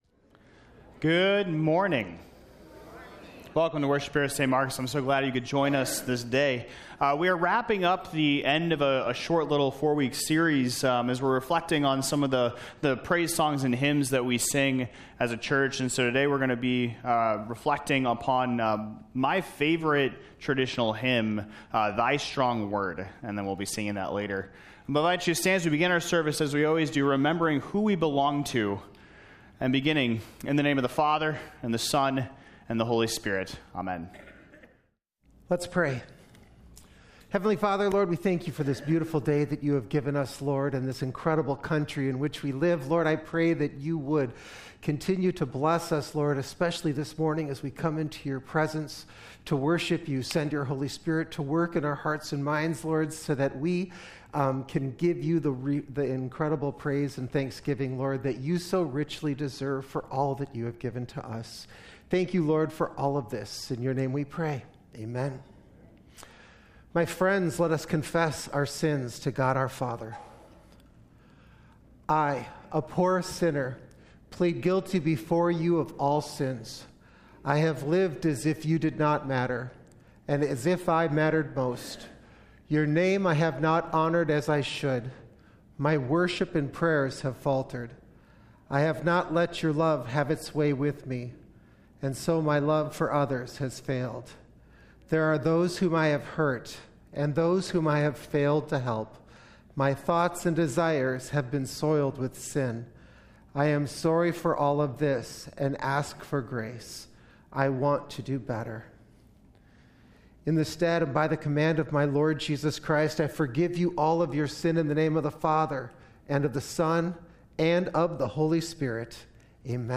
2025-July-6-Complete-Service.mp3